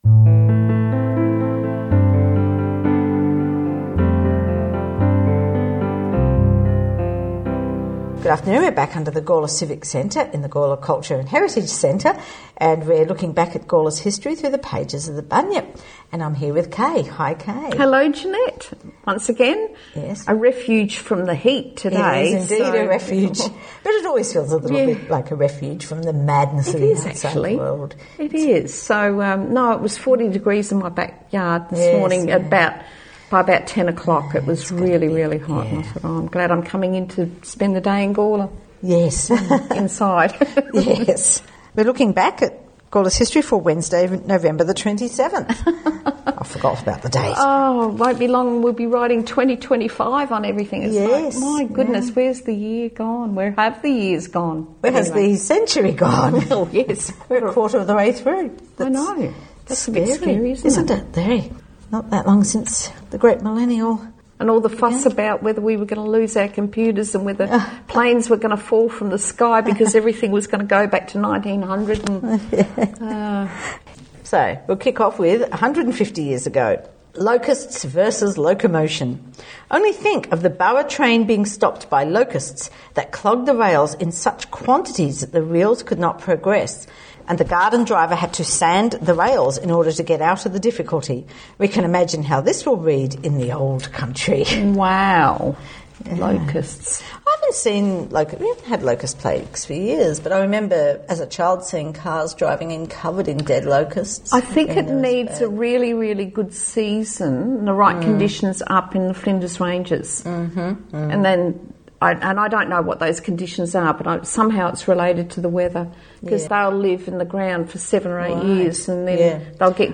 Opening and closing music